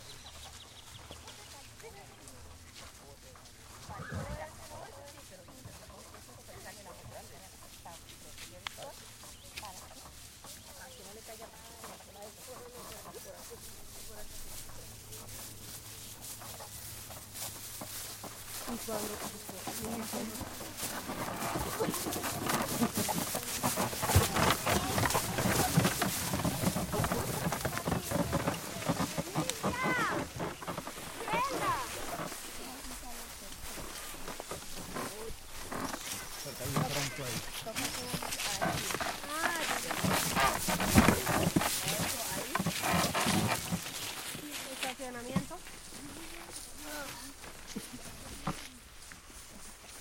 哥伦比亚 " 木头车被一群说西班牙语的人推过高高的草丛 嘎吱嘎吱响个不停
描述：一群人用西班牙拨浪鼓吱吱声推了一下木车推高草
Tag: 西班牙语 木材 吱吱声 拨浪鼓